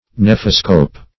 Nephoscope \Neph"o*scope\, n. [Gr. ne`fos a cloud + -scope.]